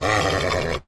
Звуки жеребца
Похоже на рычание